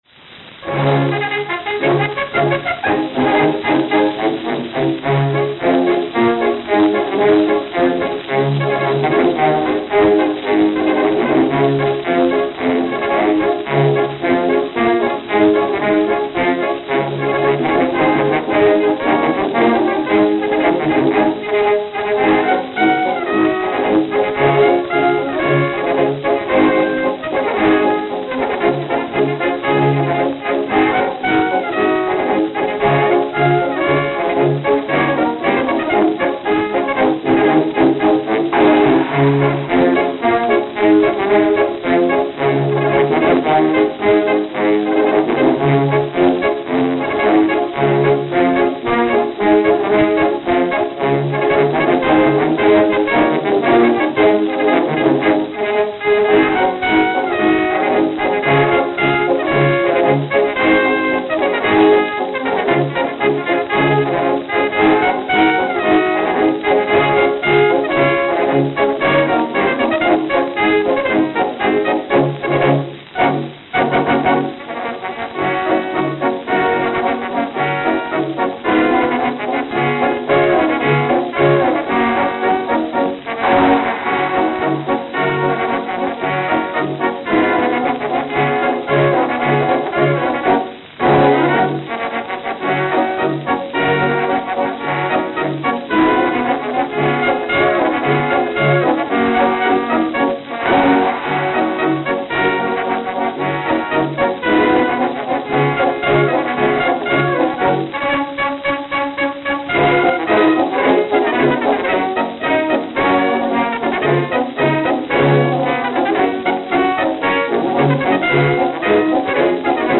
New York, New York New York, New York